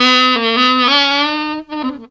COOL SAX.wav